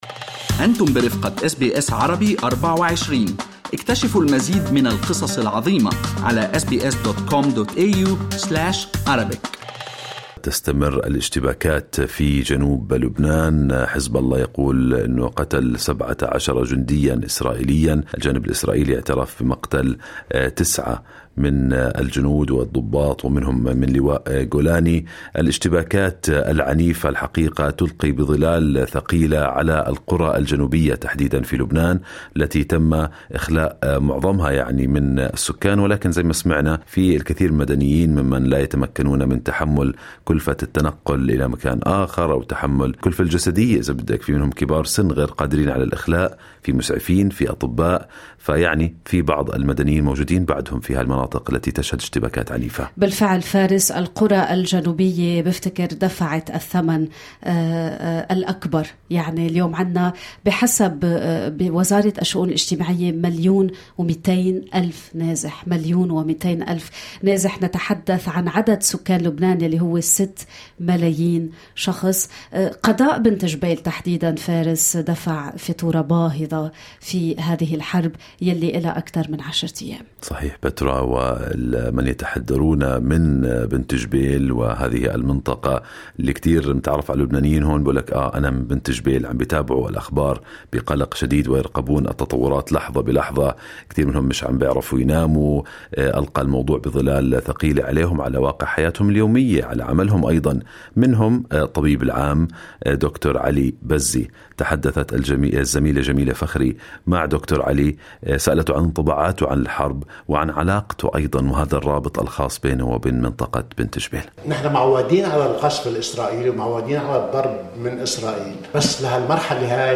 واستعرضنا آراء ثلة من أفراد الجالية العربية.